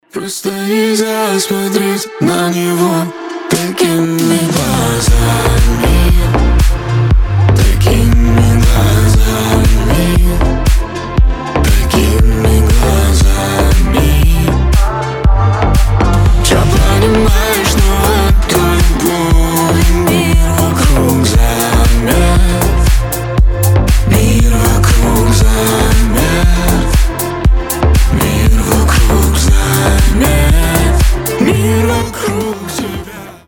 • Качество: 320, Stereo
красивые
deep house
дуэт
чувственные